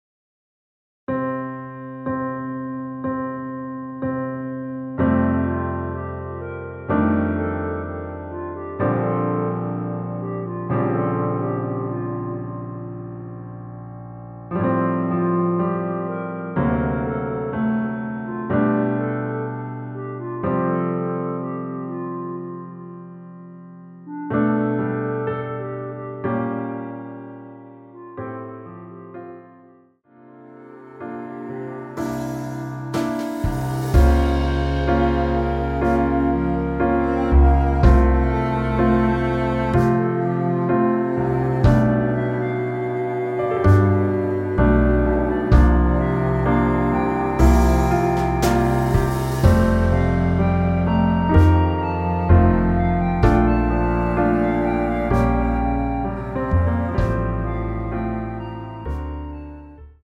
원키에서(-2)내린 멜로디 포함된 MR입니다.
앞부분30초, 뒷부분30초씩 편집해서 올려 드리고 있습니다.
중간에 음이 끈어지고 다시 나오는 이유는